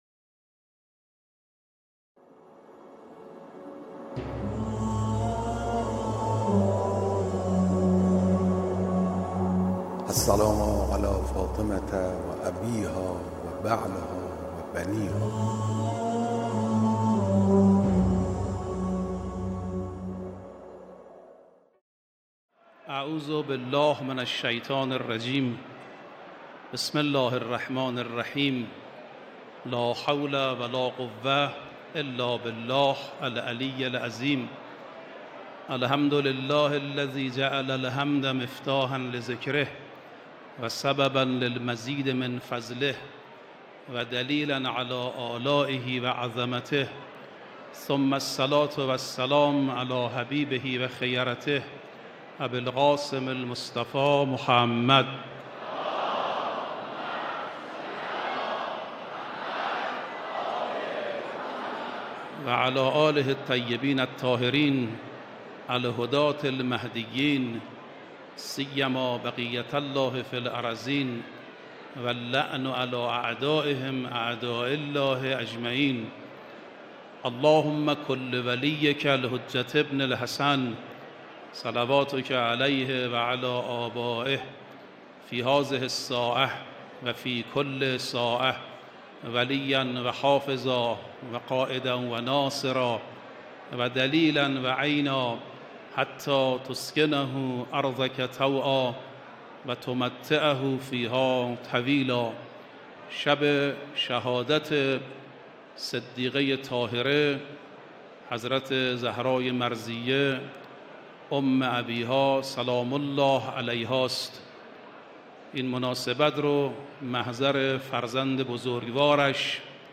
مراسم عزاداری شب شهادت حضرت ‌زهرا سلام‌الله‌علیها در حسینیه امام خمینی(ره)
سخنرانی